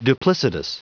Prononciation du mot : duplicitous